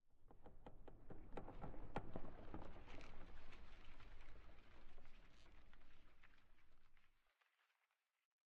pale_hanging_moss6.ogg